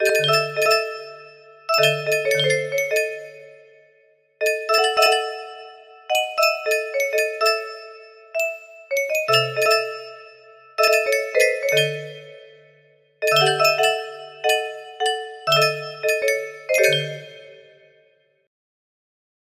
1 music box melody